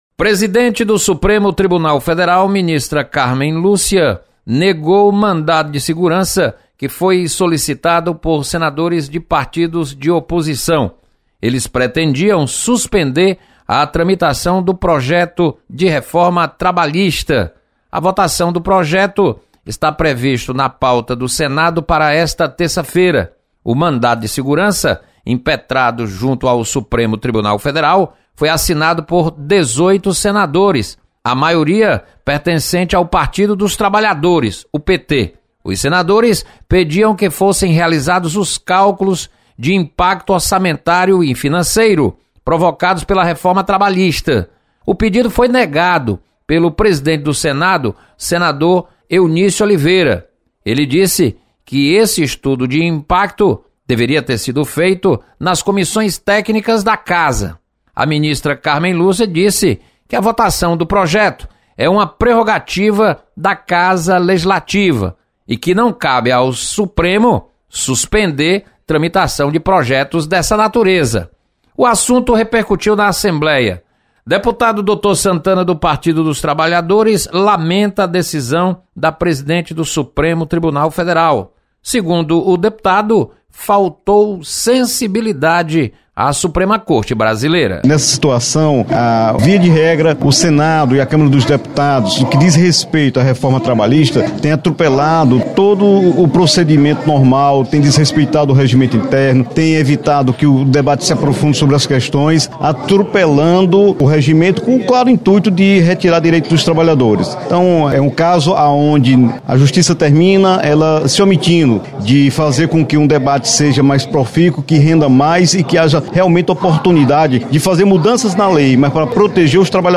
Ministra Carmem Lúcia rejeita mandado de segurança contra reforma trabalhista. Repórter